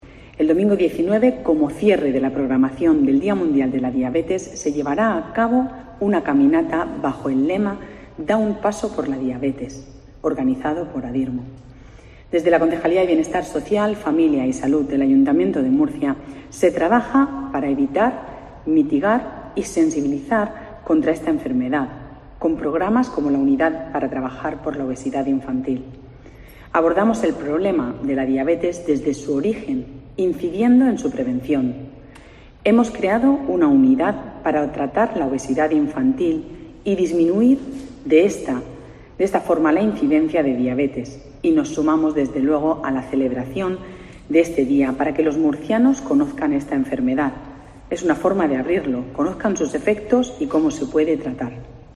Pilar Torres, concejala de Bienestar Social, Familias y Salud